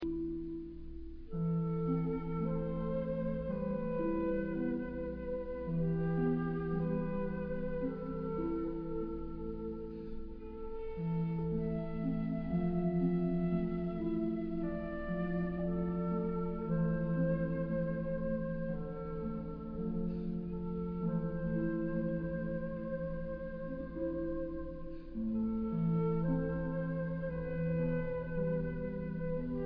somber